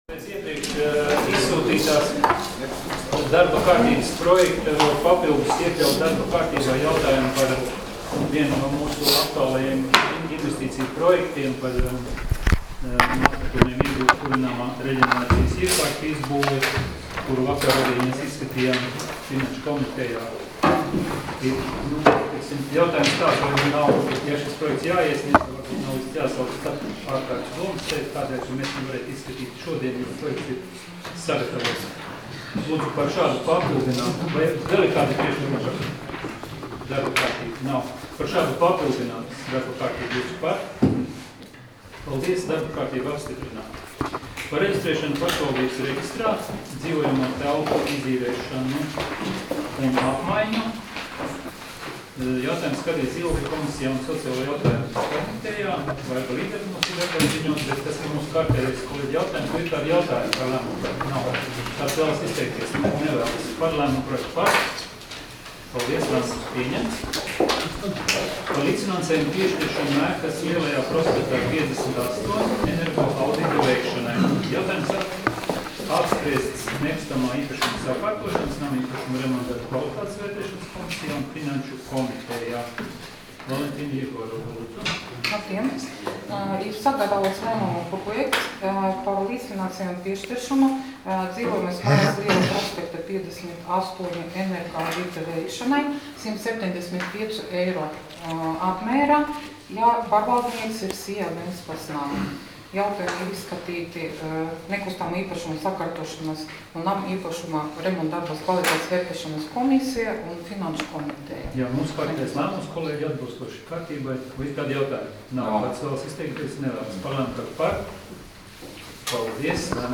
Domes sēdes 12.10.2018. audioieraksts